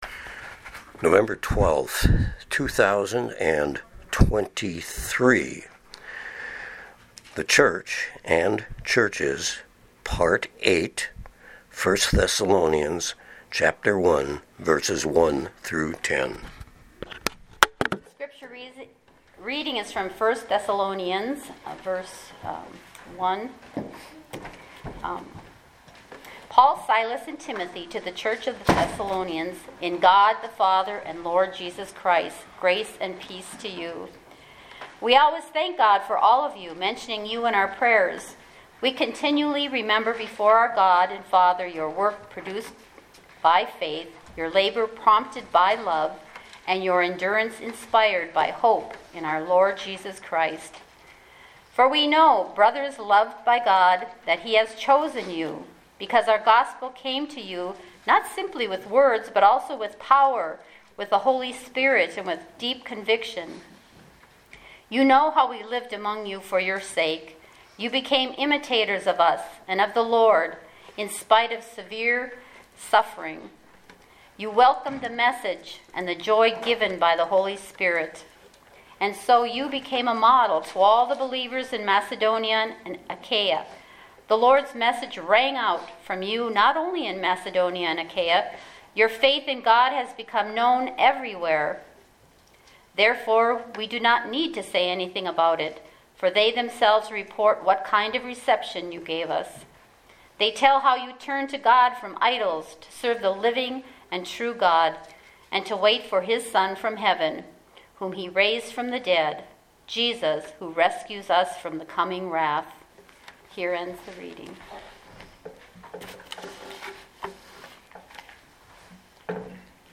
Sermons | Sand Lake Chapel